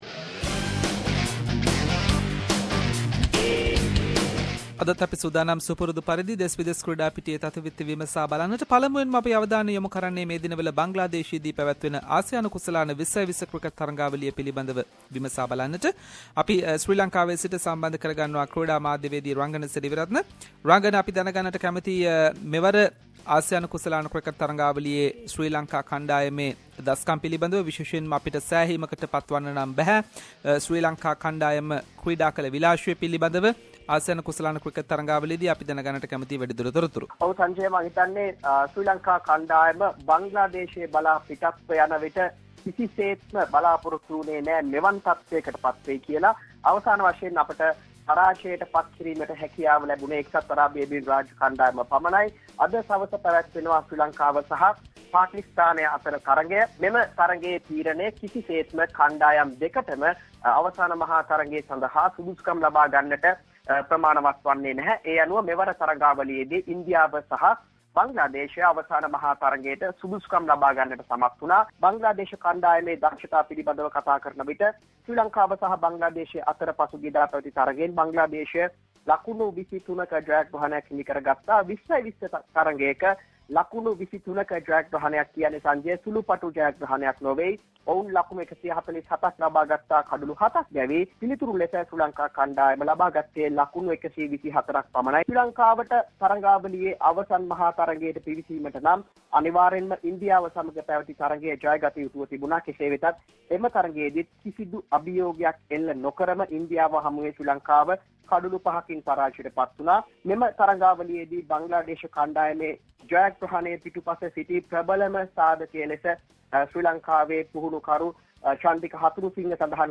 In this weeks SBS Sinhalese sports wrap…. Latest from Asia cup cricket tournament, T20 World cup preparation, and many more sports news.